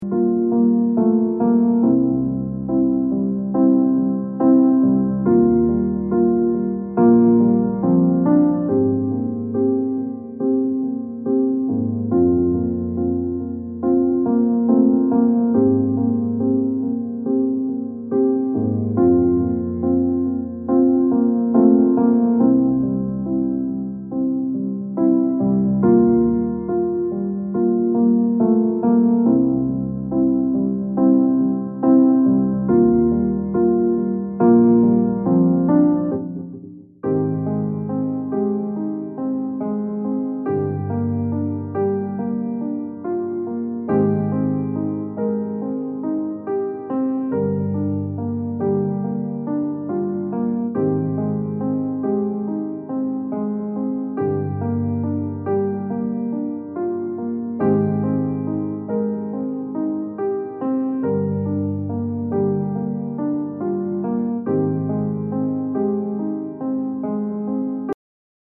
Reizarme Musik für Hochsensible